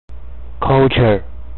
這才是道地的美式發音喔！